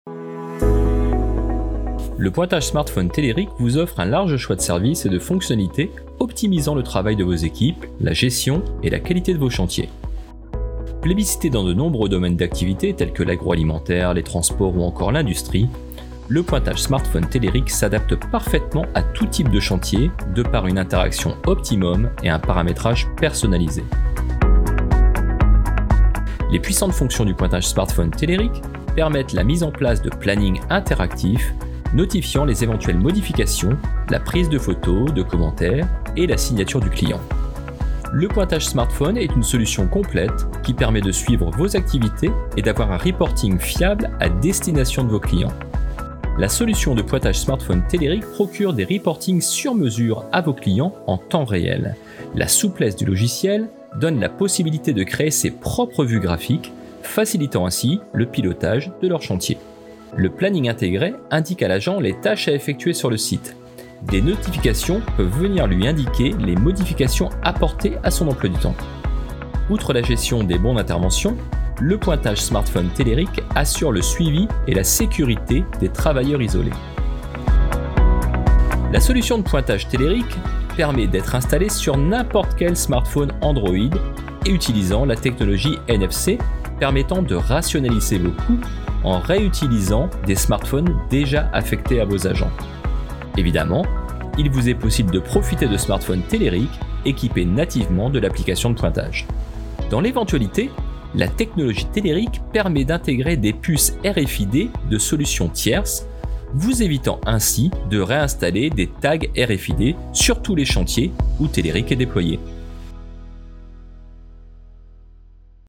Voix-Off-Smartphone.mp3